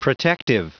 Prononciation du mot protective en anglais (fichier audio)